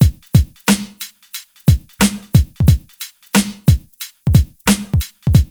HM90BEAT1 -L.wav